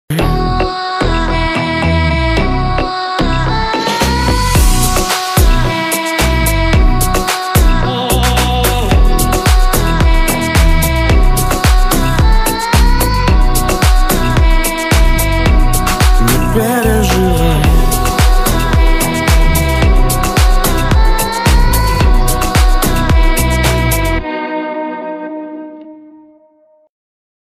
• Качество: 192, Stereo
мужской голос
ритмичные
мотивирующие
Dance Pop